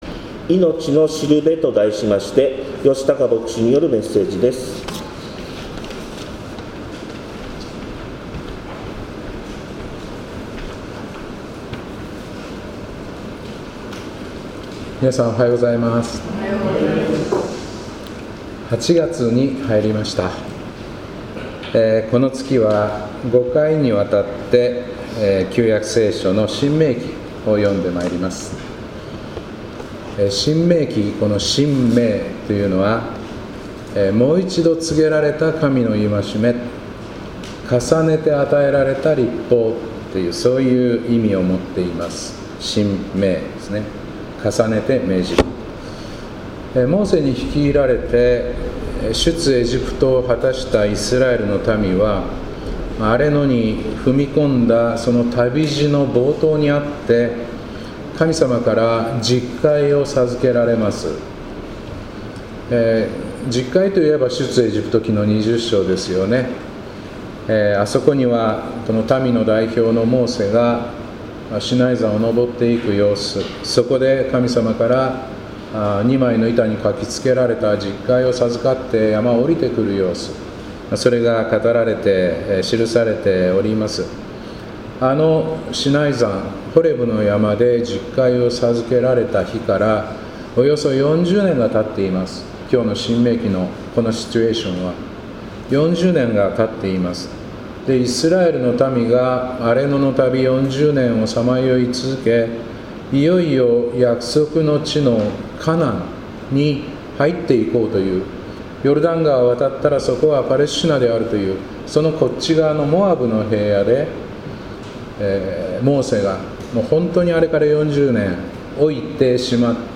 2025年8月3日礼拝「いのちの標」